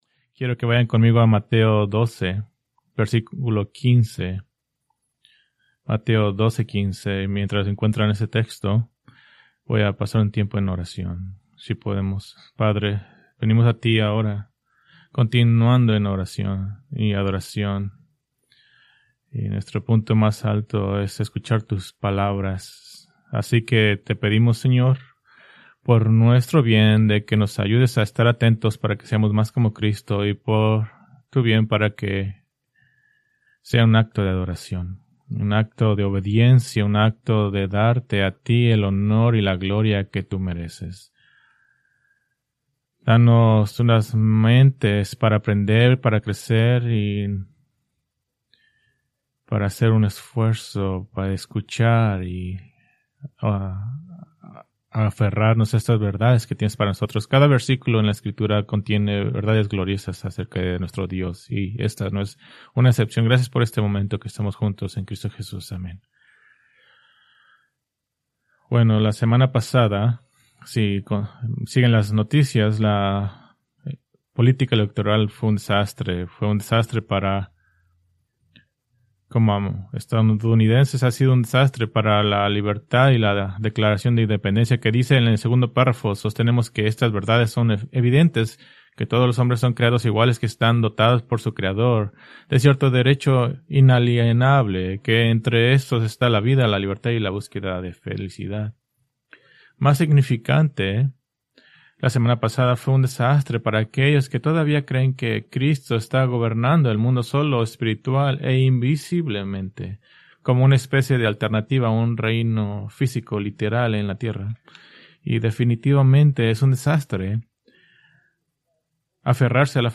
Preached November 9, 2025 from Mateo 12:15-21